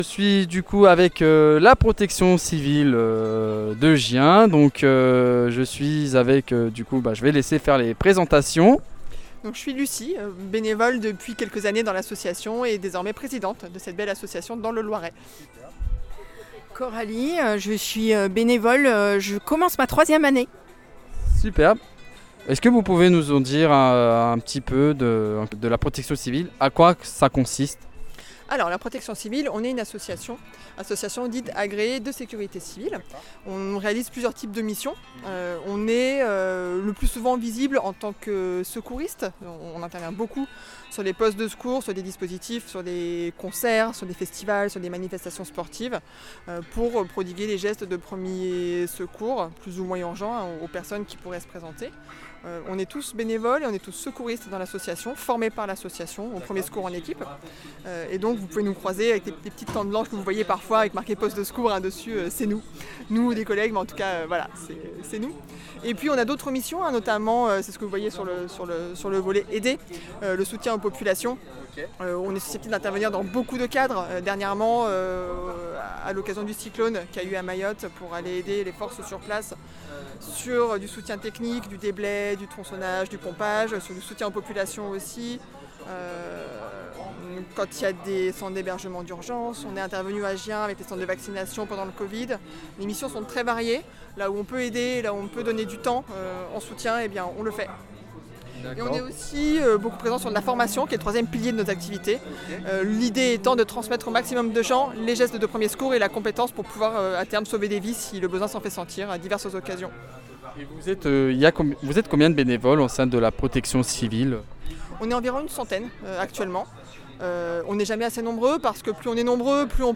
Fête des associations de Gien 2025 - Protection Civile du Loiret (antenne Gien)